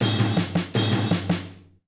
British SKA REGGAE FILL - 13.wav